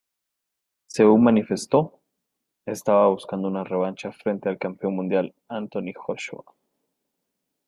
re‧van‧cha